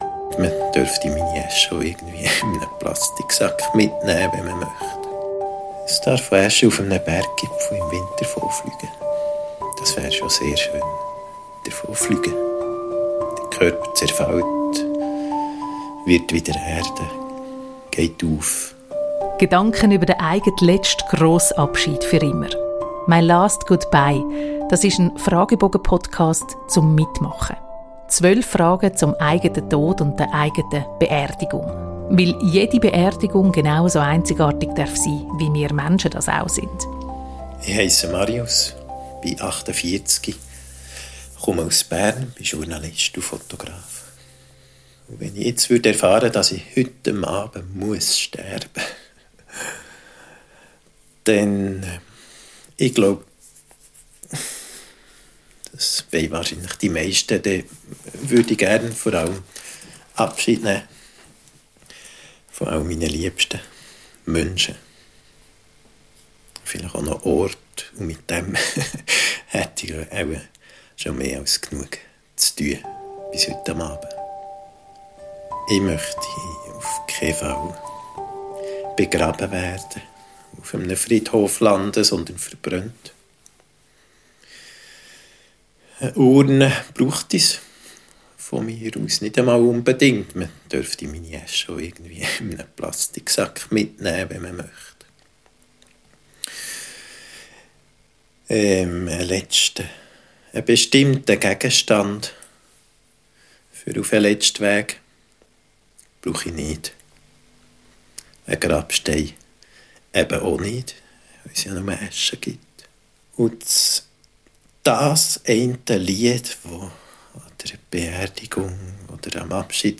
ruhig, bedacht, intim.